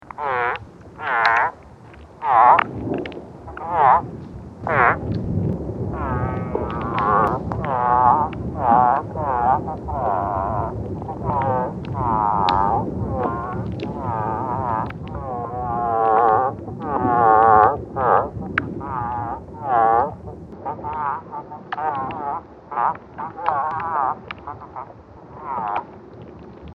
A recording of an unknown creature as heard through a hydrophone that was in contact with the roots of the Pando aspen grove in south-central Utah.